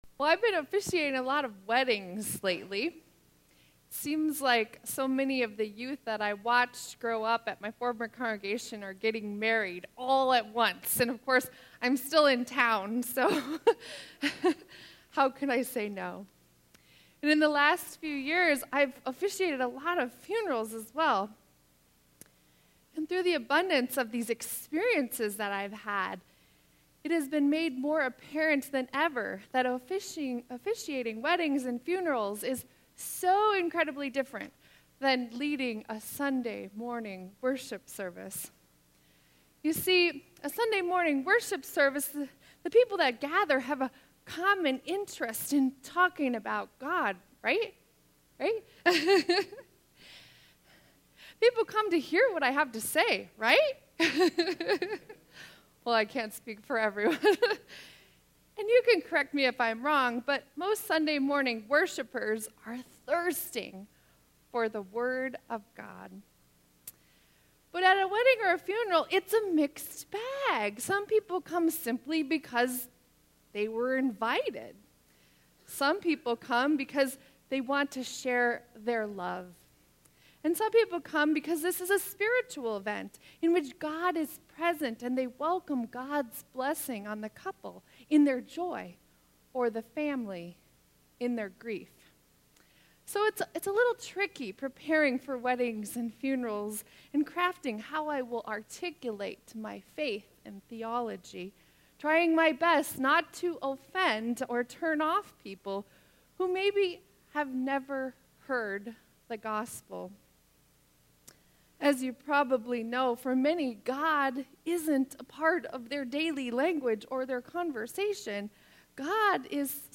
Sermon 10.15.2017